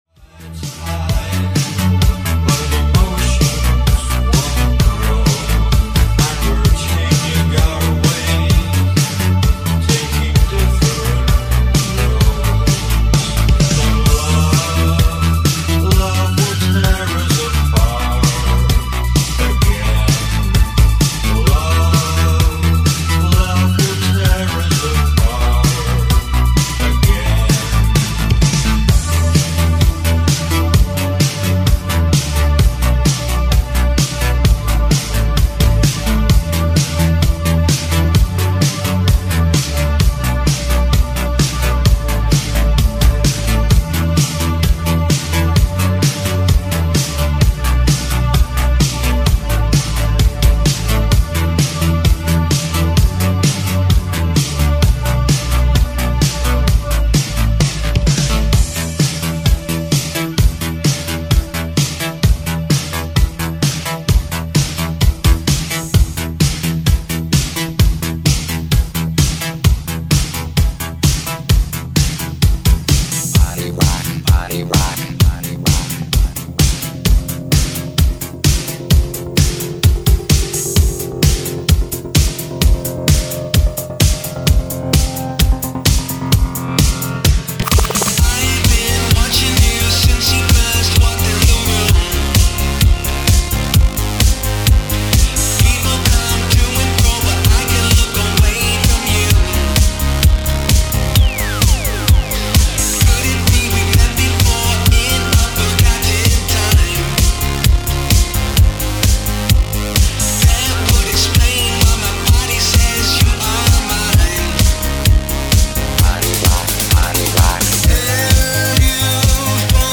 PREVIEW MIX